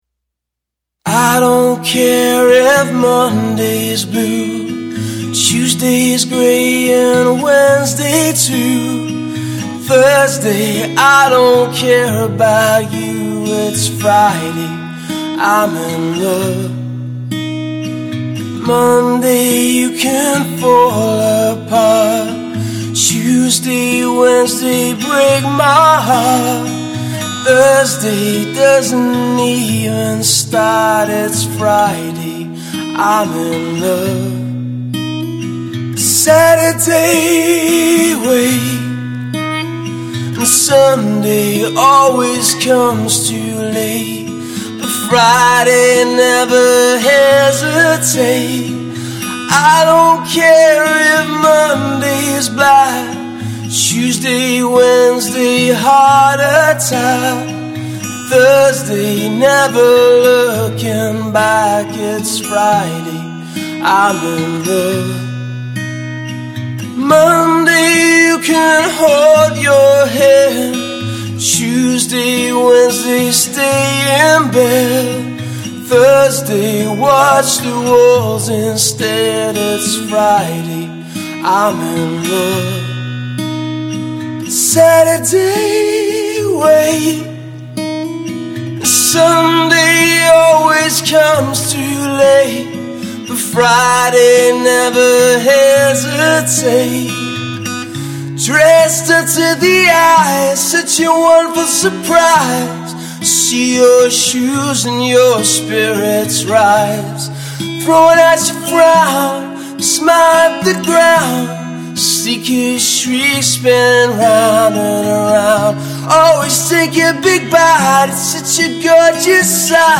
Solo singer Guitarist in Yorkshire